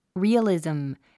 発音 ríːəlìzm リィアリズム